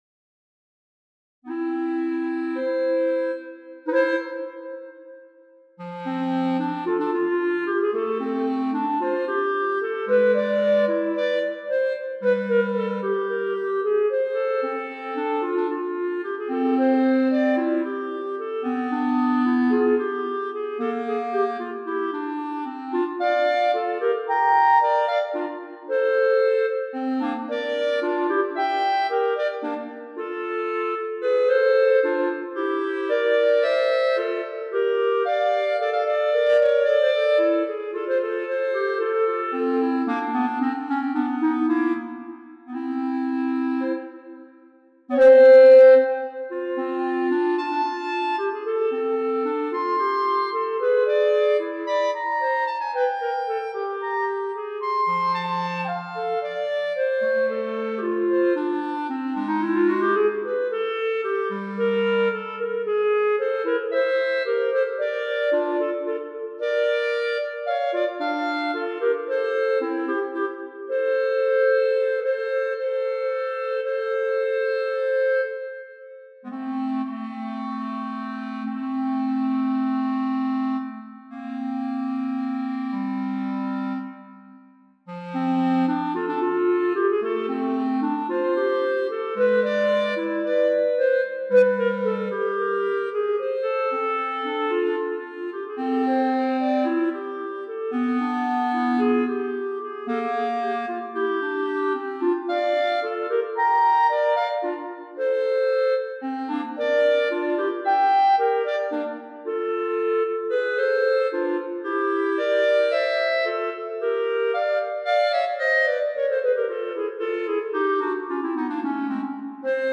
A little ragtime for two clarinets passes the solo and accompaniment roles back and forth, with short episodes between repetitions of the theme in thirds and syncopations.
Rag_for_Two_Clarinets.mp3